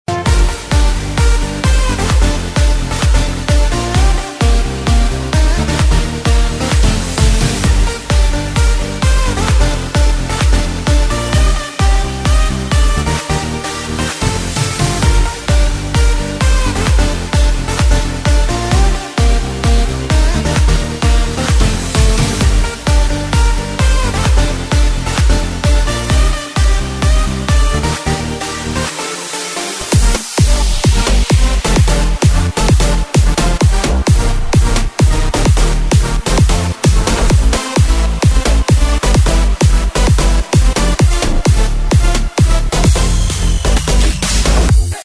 אגב, לנוחיותכם, אני מעלה פה הקלטה פנימית של הטראק הזה